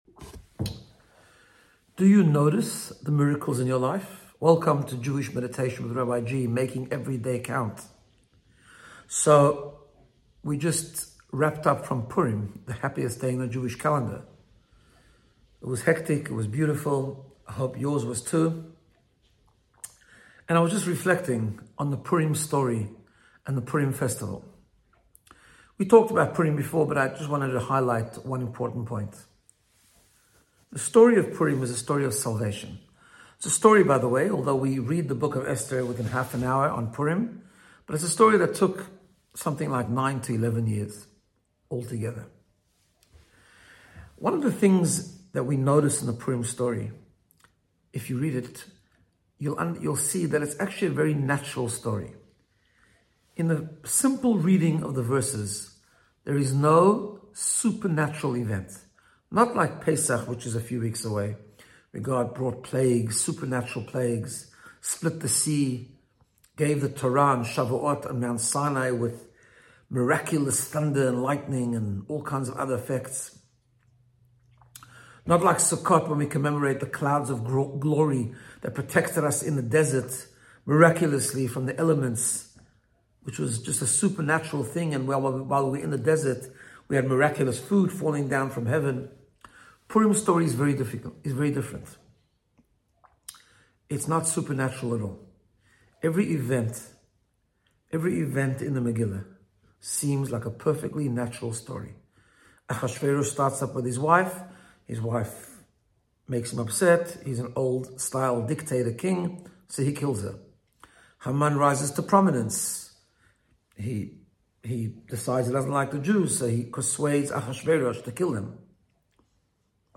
Jewish Meditation